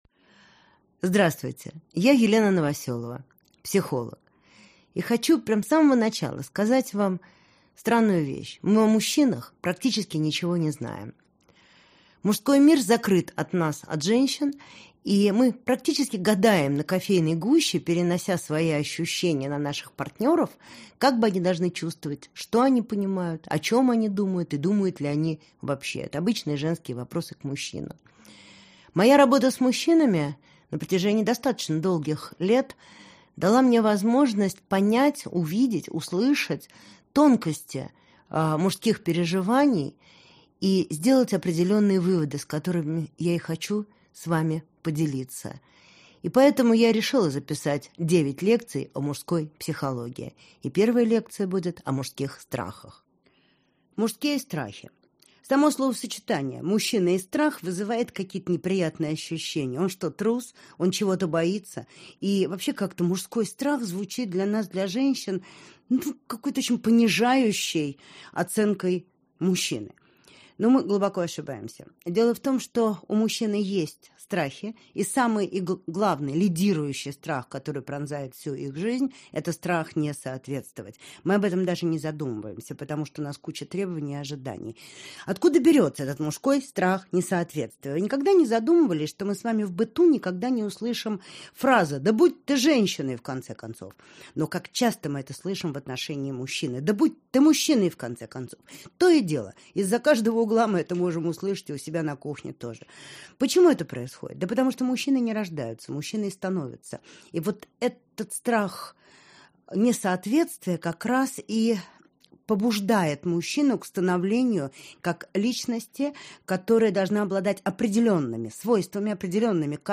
Аудиокнига Мужское. Начало. Часть 1 | Библиотека аудиокниг